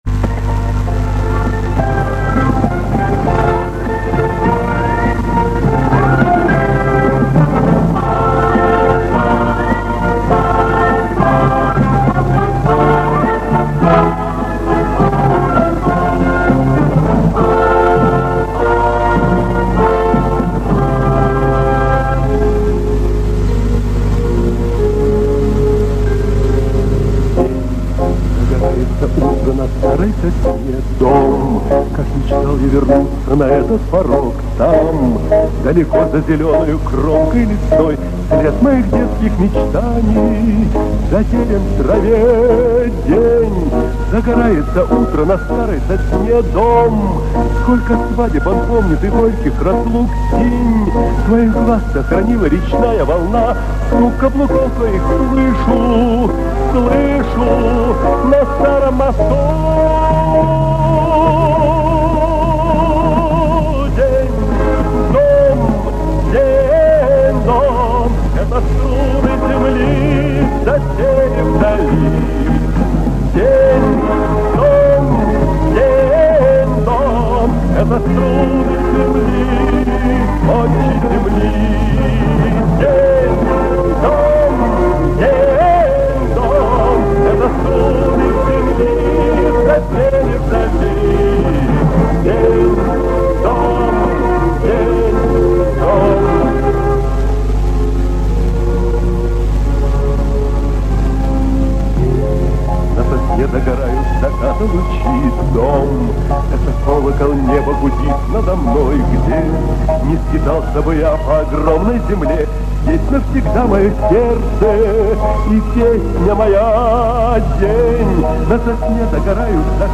Режим: Stereo